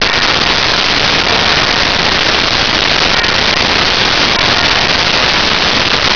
Bells004
bells004.wav